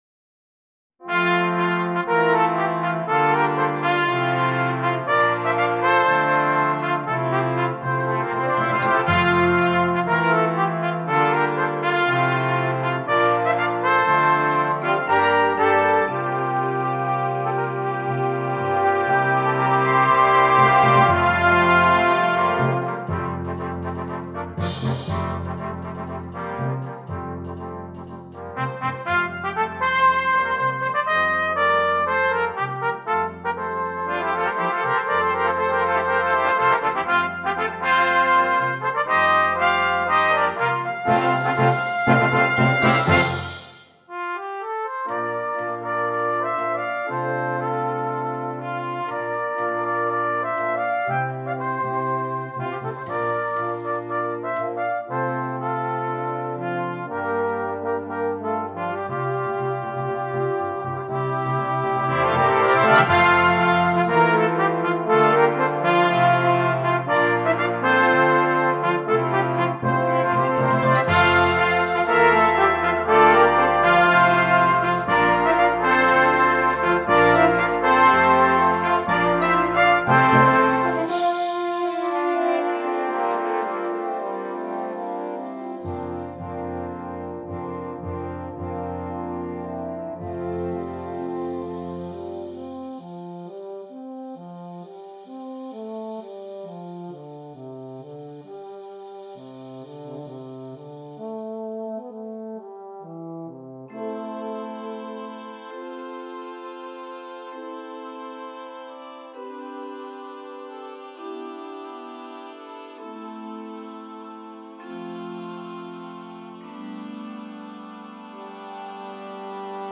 Brass Choir (5.4.3.1.1.perc)